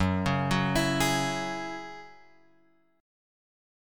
F#7sus2 chord {2 4 4 x 5 4} chord